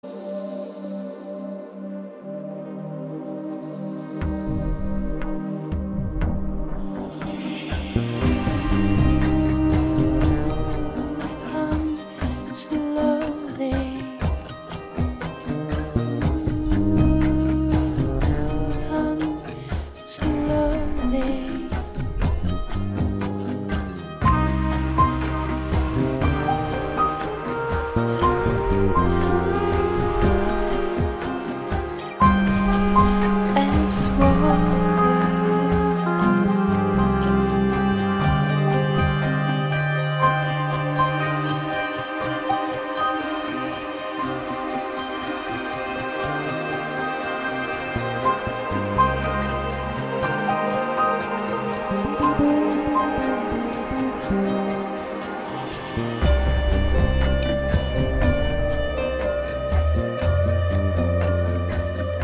Genre: world
Prelogue. Ambient soundscape   4:33